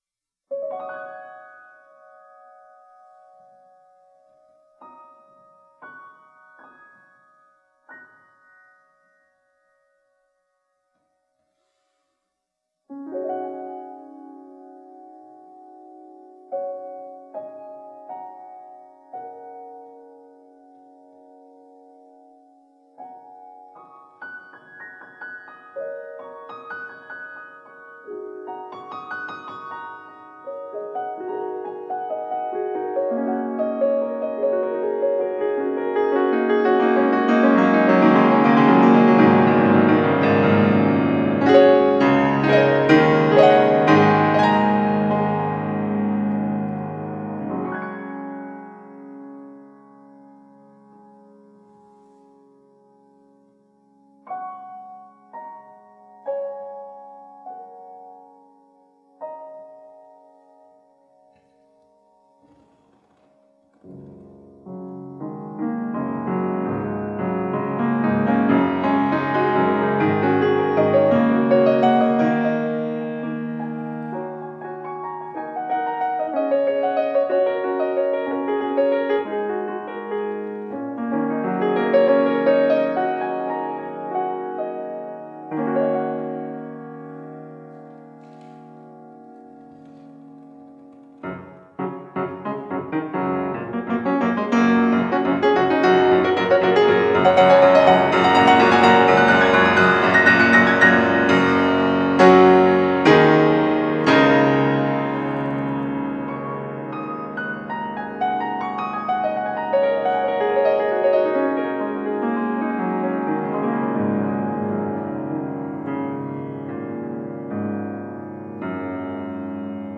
钢琴独奏